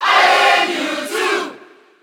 Category:Crowd cheers (SSB4) You cannot overwrite this file.
Mewtwo_Cheer_French_SSB4.ogg.mp3